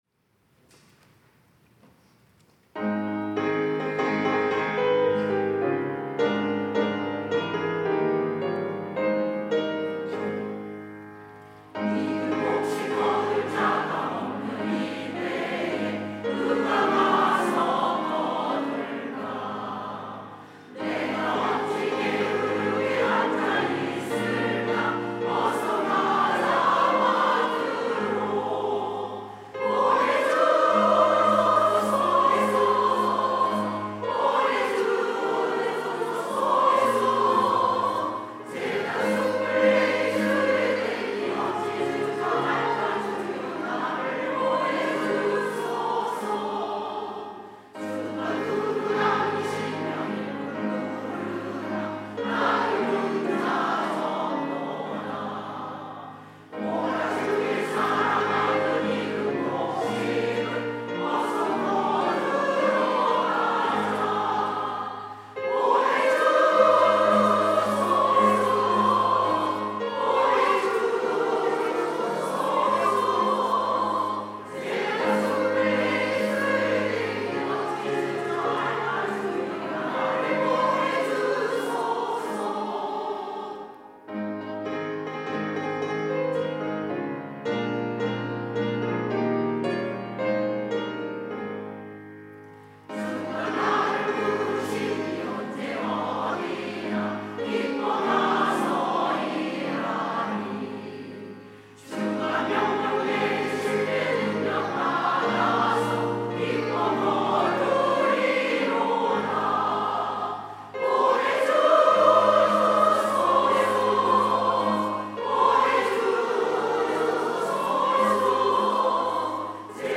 시니어대학 찬양대